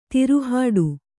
♪ tiru hāḍu